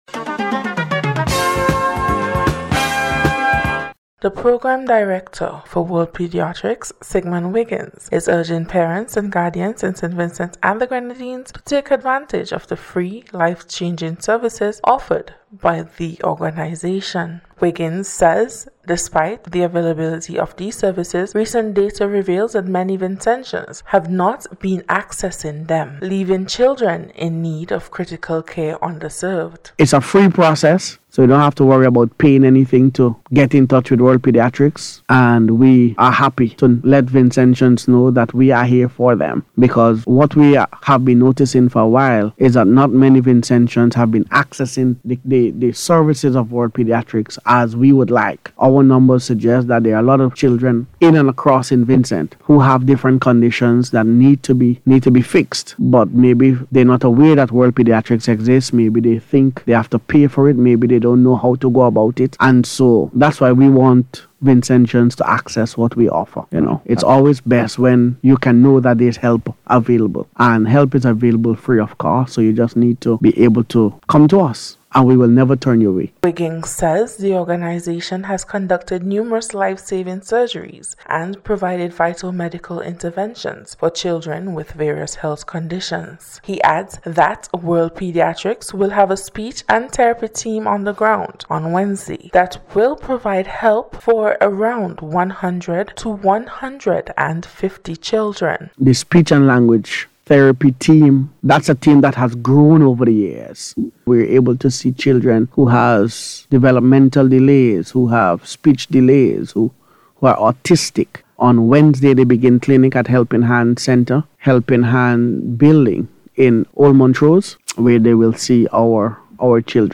NBC’s Special Report- Monday 27th January,2025